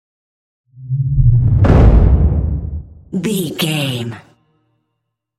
Dramatic deep whoosh to hit trailer
Sound Effects
Atonal
dark
futuristic
intense
tension
woosh to hit